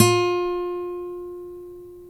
Index of /90_sSampleCDs/Roland L-CD701/GTR_Nylon String/GTR_Nylon Chorus
GTR NYLON20D.wav